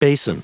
Prononciation du mot : basin